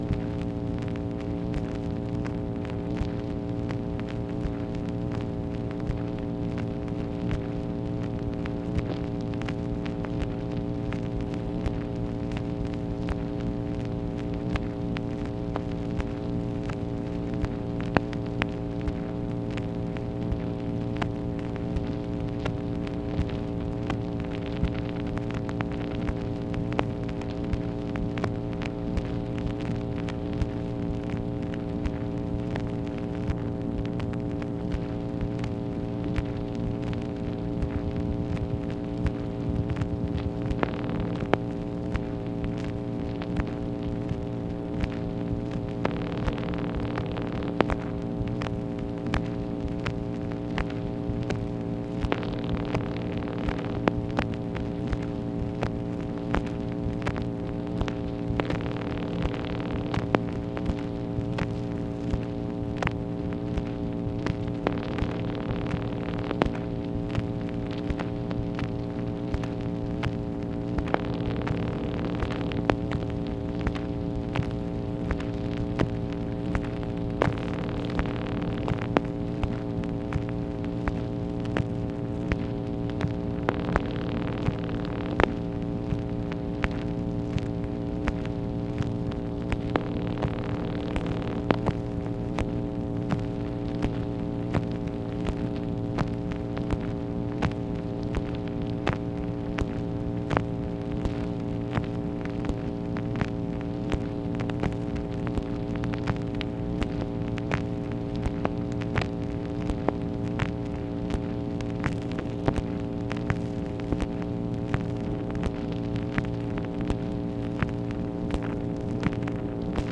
MACHINE NOISE, August 13, 1964